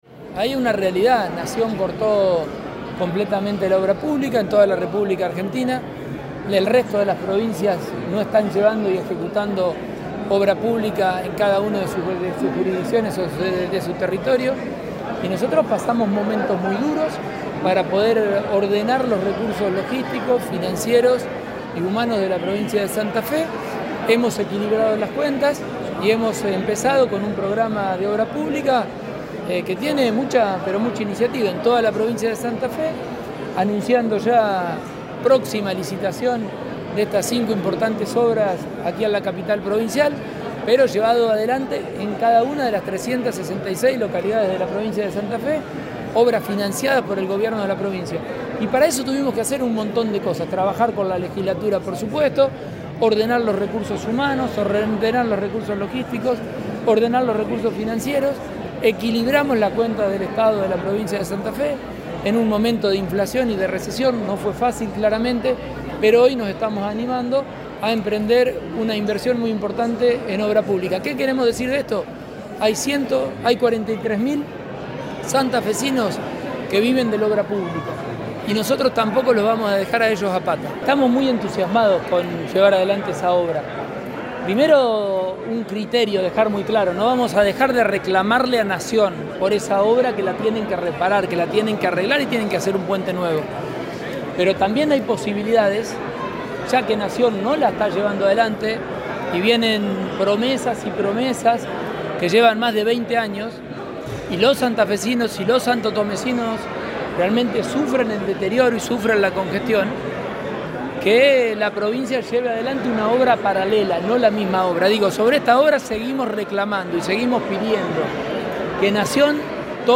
Declaraciones Pullaro y Poletti